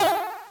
jumpR1.ogg